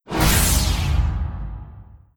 snd_ui_modelshow.wav